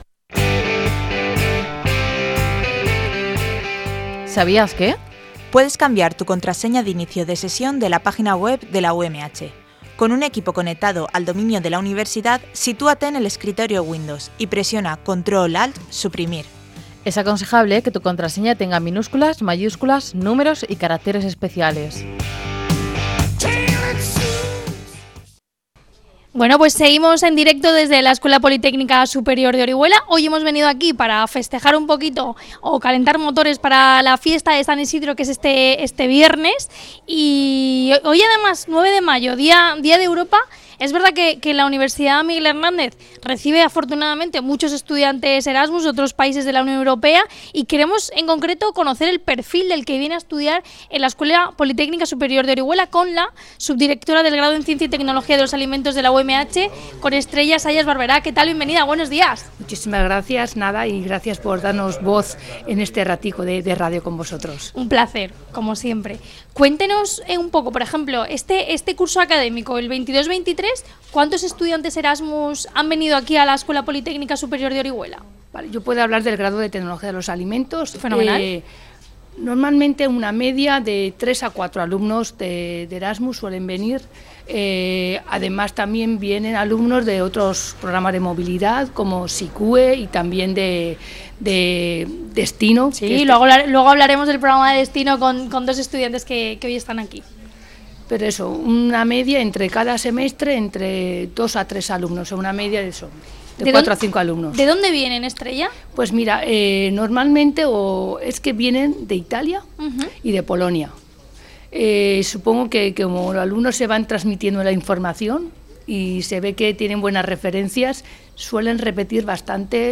Programa especial desde el campus de Orihuela-Desamparados con motivo de la celebración de San Isidro, 9 de mayo de 2023